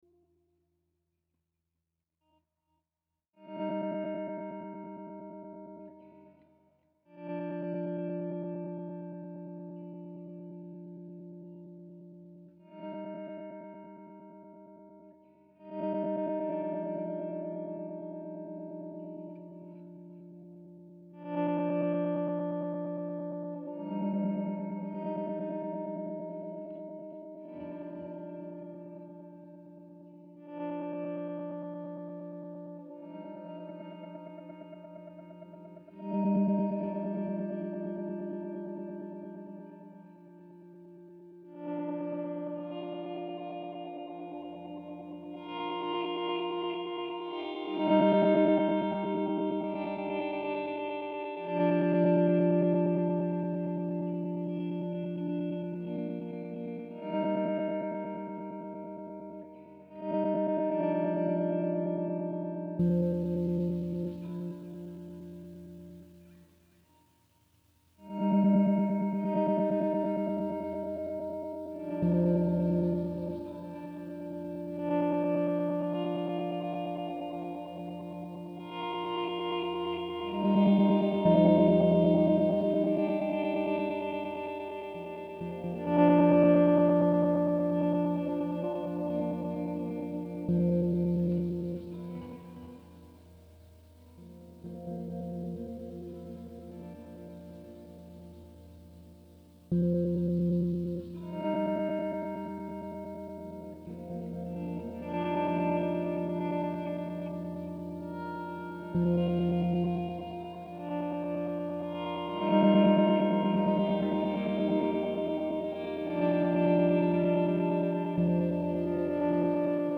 guitar
laptop
field recordings